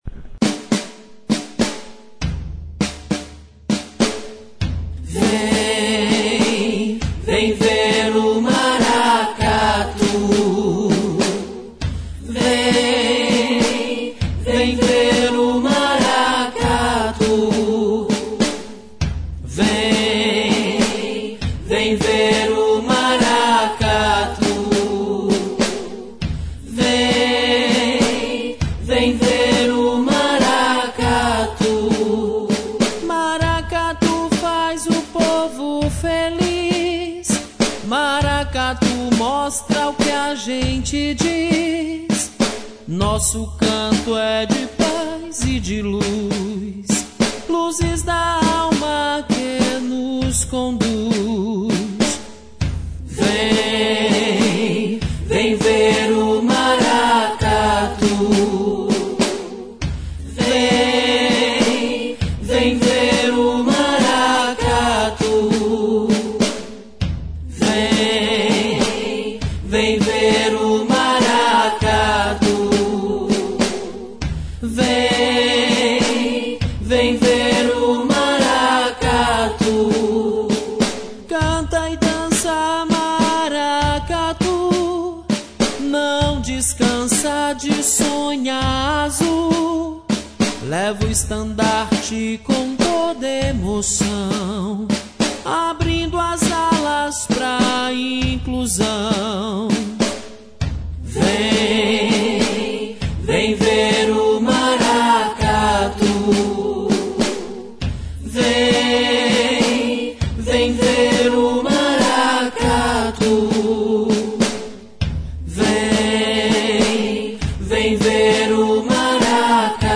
O Maracatu Luzes da Alma da Sociedade de Assistência aos Cegos